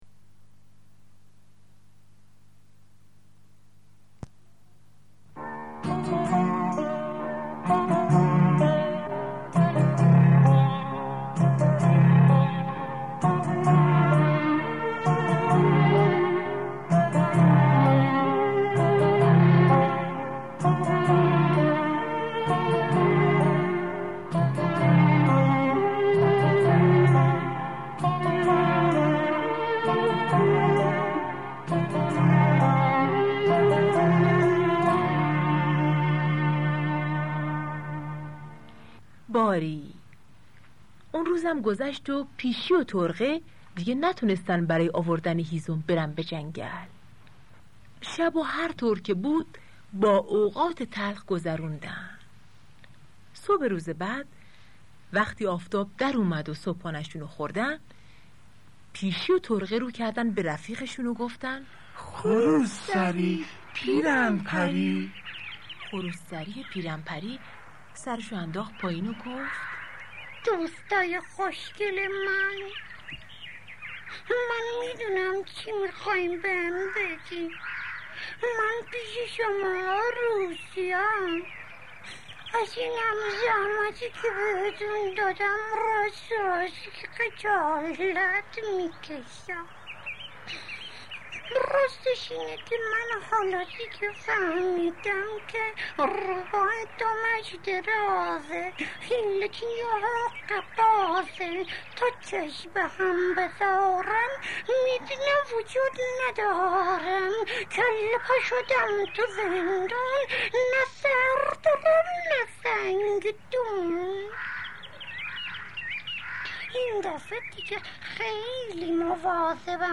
پیرهن پری 2 پخش آنلاین دانلود نسخه صوتی دکلمه دانلود / گوینده: [احمد شاملو] برچسب ها: احمد شاملو اشتراک گذاری : نظرات لطفا نظرات خود را به فارسی بنویسید و از الفبای لاتین خودداری کنید.